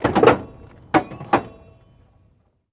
Cadillac 1964 Door Open